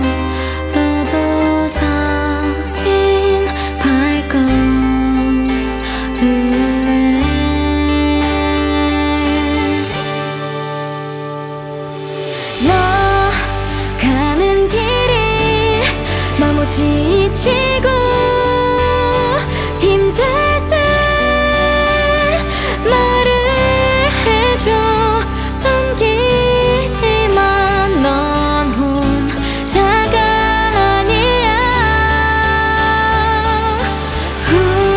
Жанр: Поп музыка / Рок
K-Pop, Pop, Rock